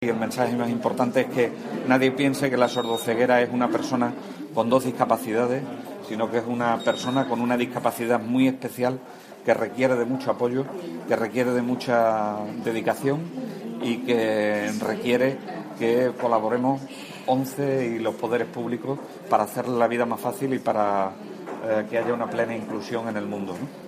consejero de Sanidad formato MP3 audio(0,18 MB) extremeño no dudó en ofrecer toda la ayuda posible para esta causa.